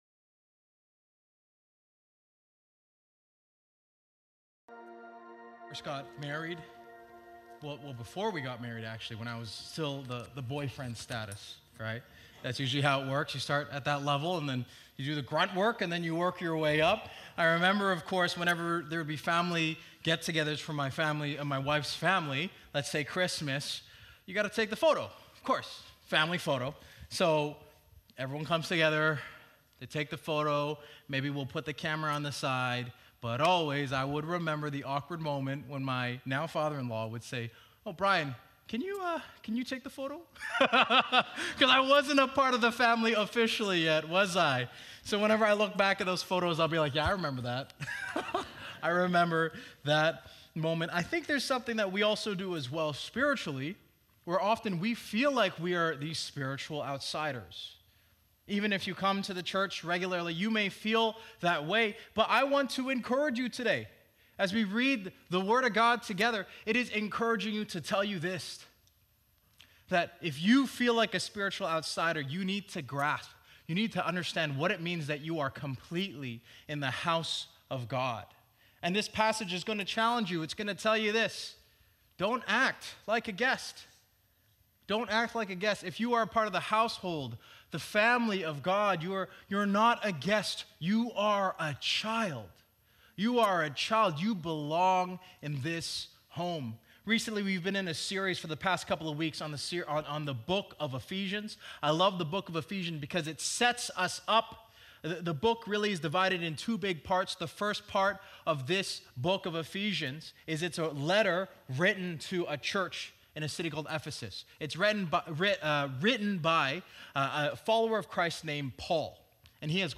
City Centre Church - Mississauga
message